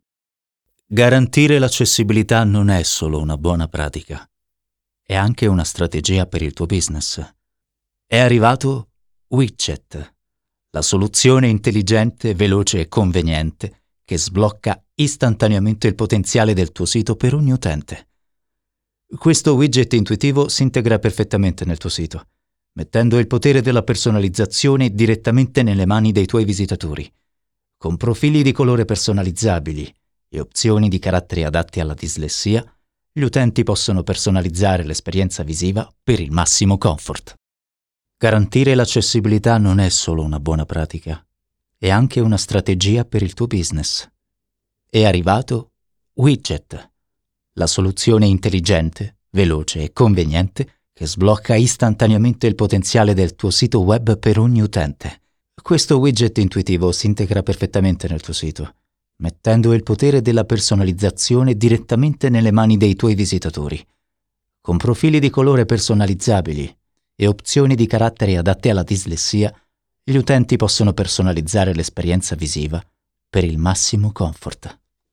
Male
Teenager (13-17), Adult (30-50)
When requested I'm capable of infusing emotional presence into the reading so as to add a layer of warmth and elegance, making the brand stand out in a lavish yet genuine manner. Elegant, warm, young versatile voice.
Explainer Video
All our voice actors have professional broadcast quality recording studios.